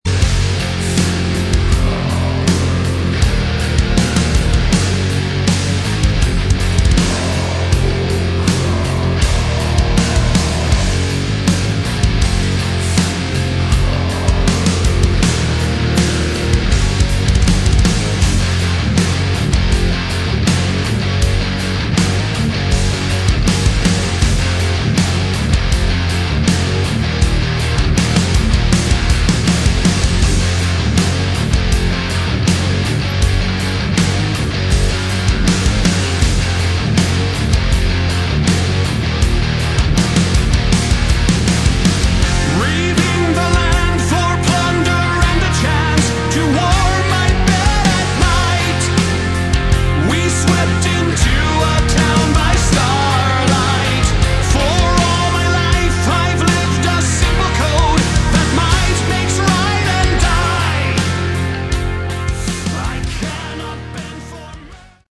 Category: Rock
bass guitar, backing vocals
drums, backing vocals
guitar, backing vocals
lead vocals, backing vocals